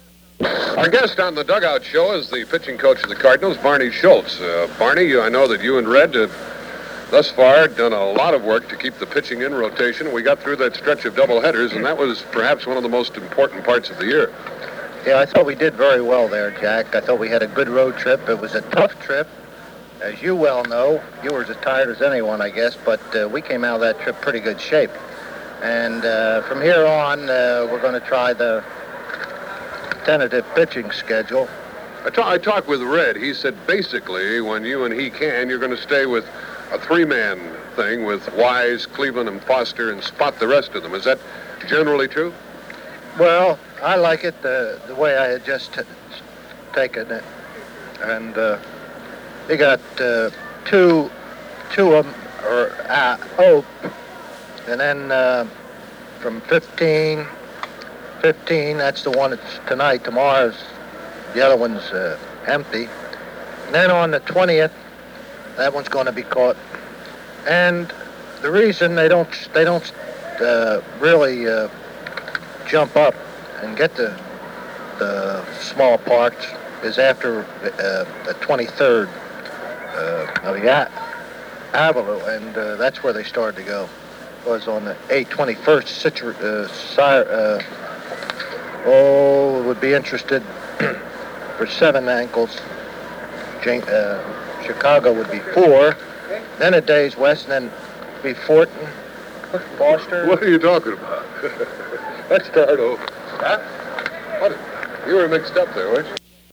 Buck, Jack and Schultz, Barney interview · St. Louis Media History Archive
Original Format aircheck